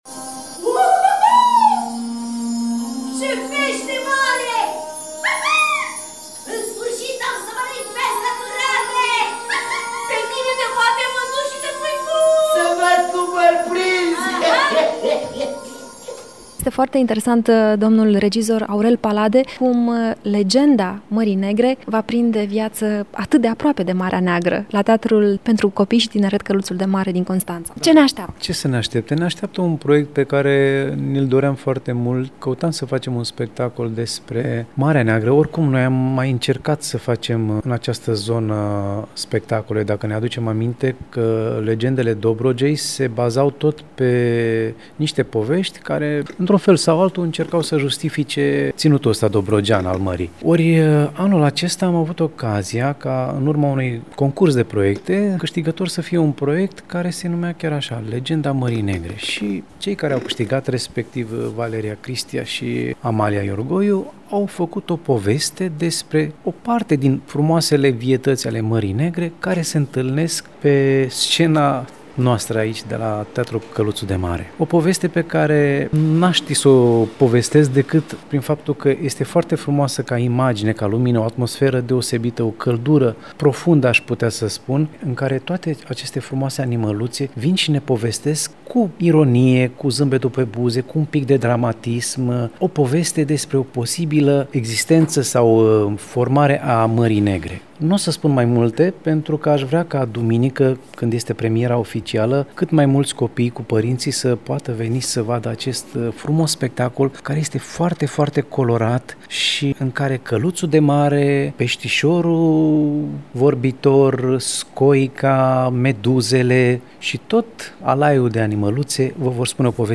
Urmează un interviu